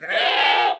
Minecraft Version Minecraft Version latest Latest Release | Latest Snapshot latest / assets / minecraft / sounds / mob / goat / screaming_pre_ram5.ogg Compare With Compare With Latest Release | Latest Snapshot
screaming_pre_ram5.ogg